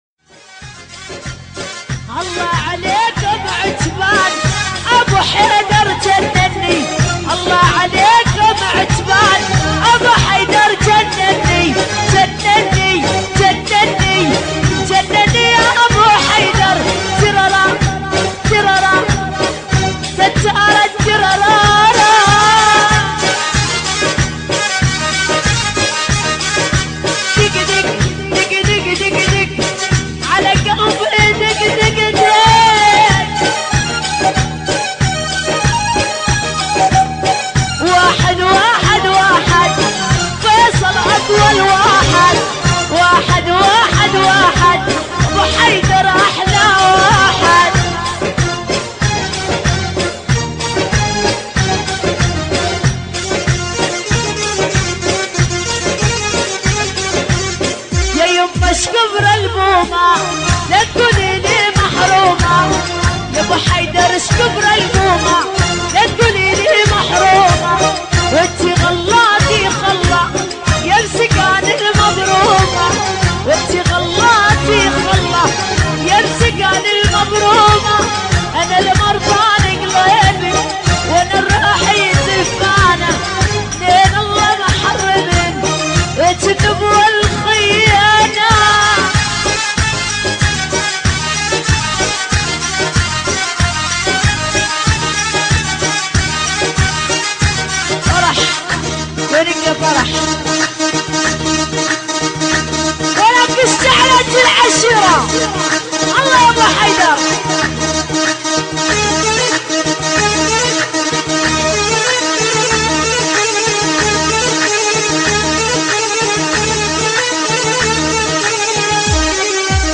دبكات